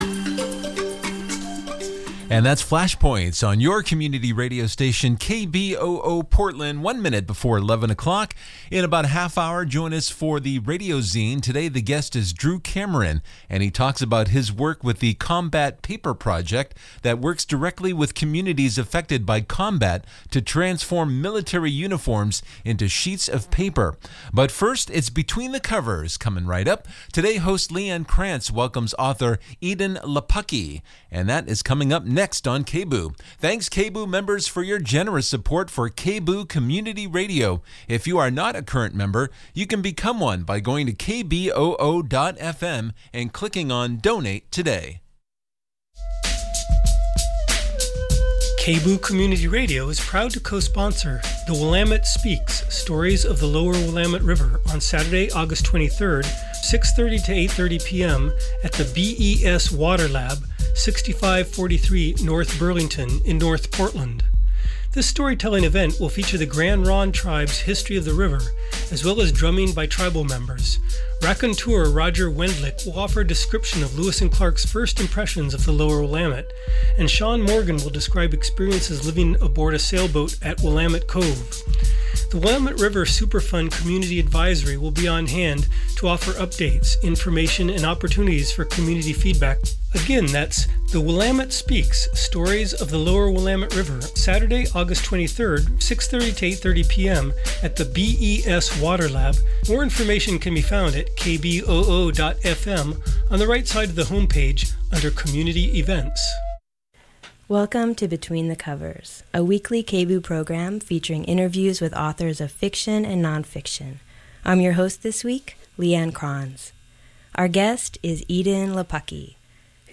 Every third Thursday from 11:00 AM to 12:00 PM A weekly show featuring interviews with locally and nationally known authors of both fiction and non-fiction.